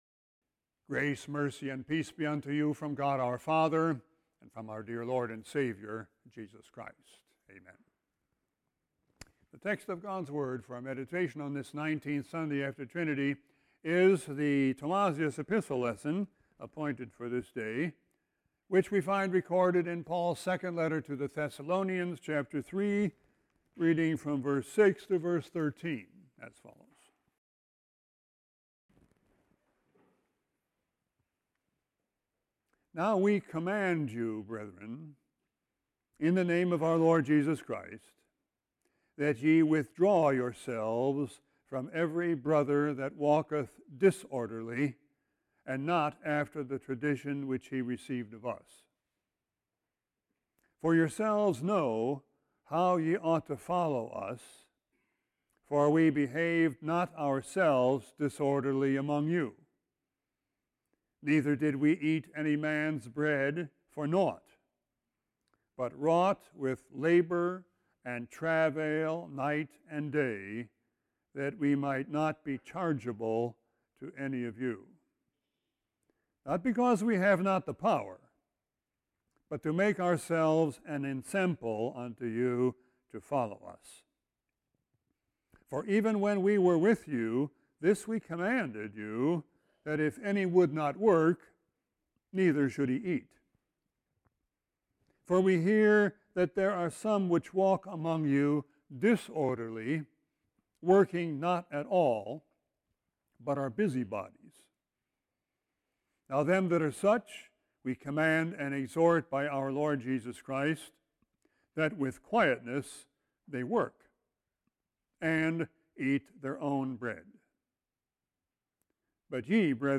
Sermon 10-7-18.mp3